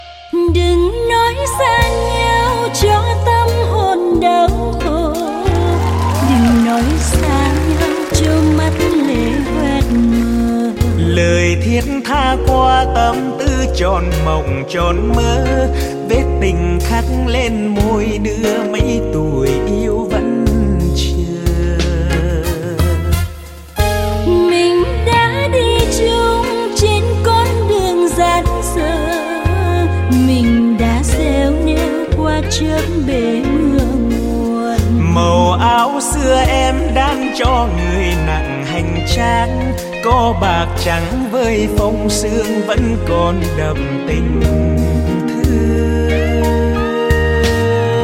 Nhạc Bolero.